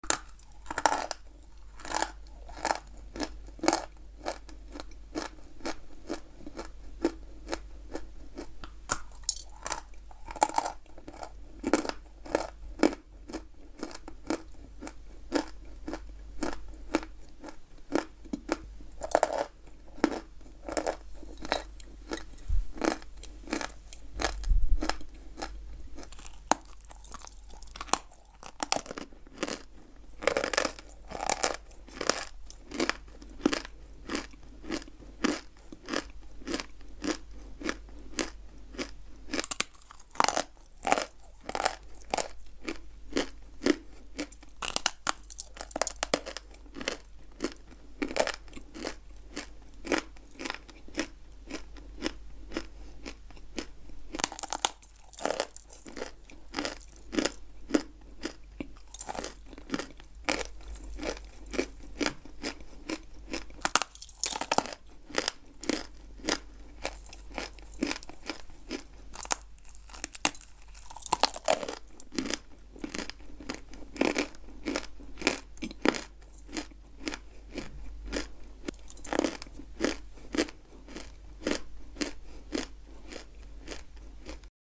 Fake Eating ❗ Fake Food Sound Effects Free Download